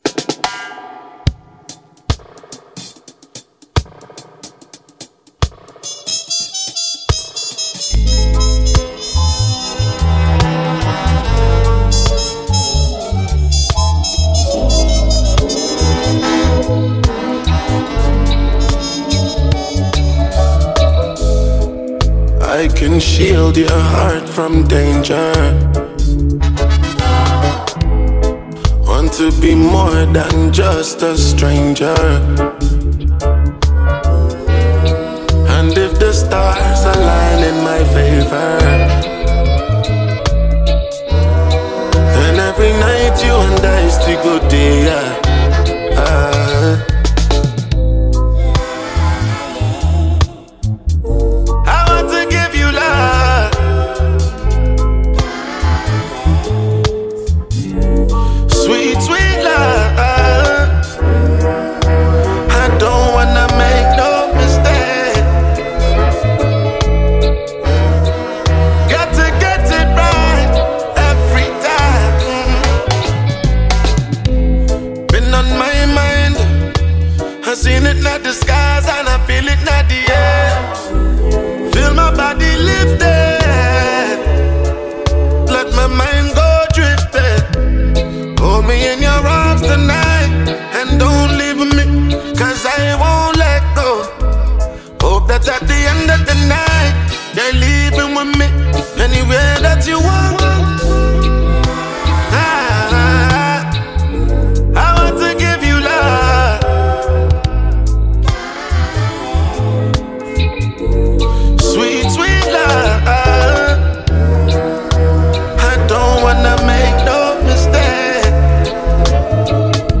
Nigerian Afro-fusion star